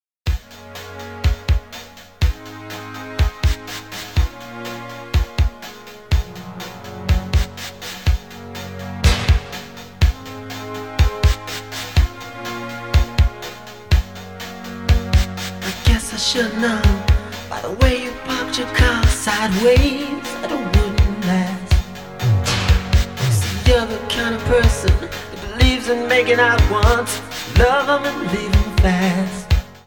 • R&B/Soul
who also performs a guitar solo